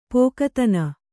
♪ pōkatana